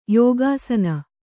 ♪ yōgāsana